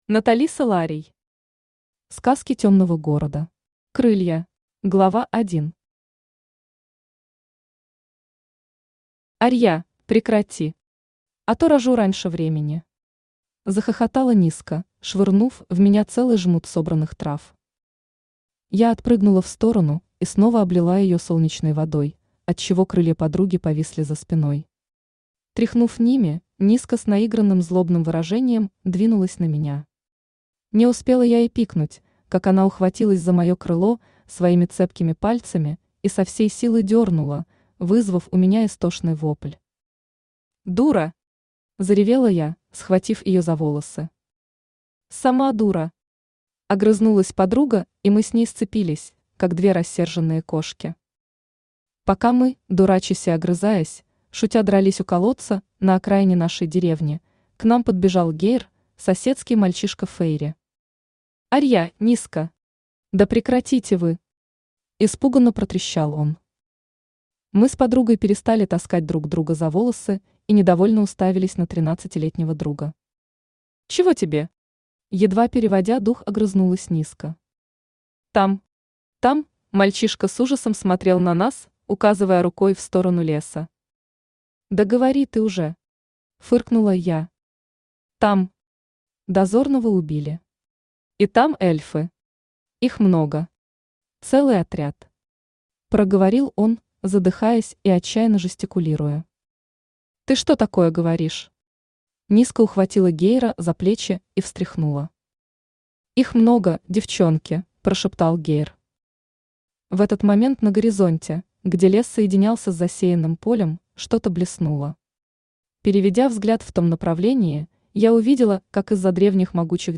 Аудиокнига Сказки темного города. Крылья | Библиотека аудиокниг
Крылья Автор Наталиса Ларий Читает аудиокнигу Авточтец ЛитРес.